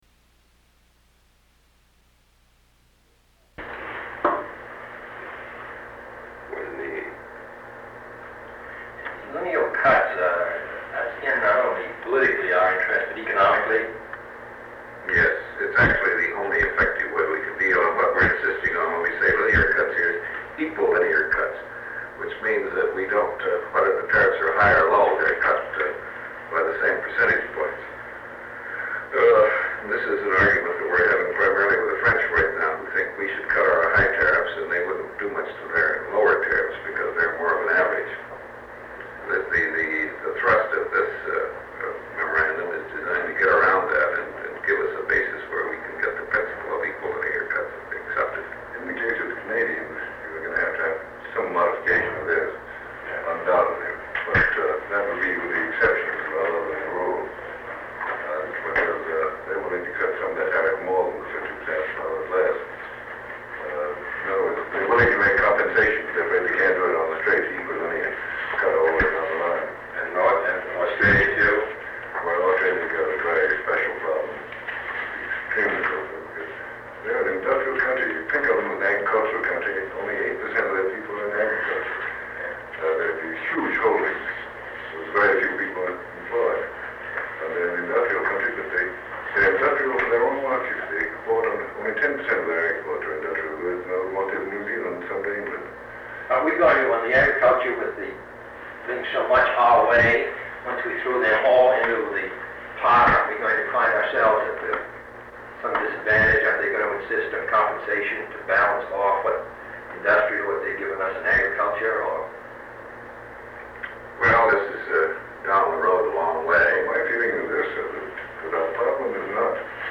Sound recording of a meeting between President John F. Kennedy, Under Secretary of State George Ball, Special Representative for Trade Negotiations Christian Herter, and Deputy Special Assistant for National Security Affairs Carl Kaysen.